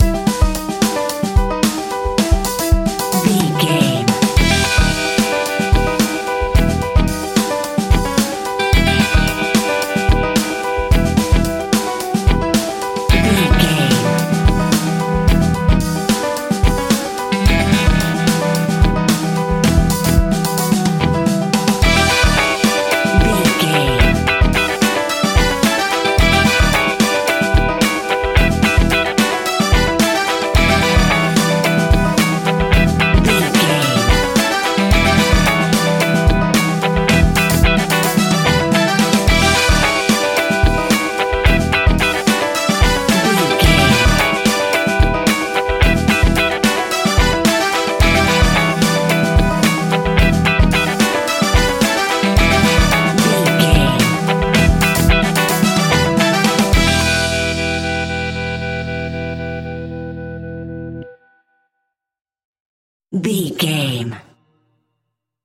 Aeolian/Minor
flamenco
latin
salsa
uptempo
bass guitar
percussion
saxophone
trumpet
fender rhodes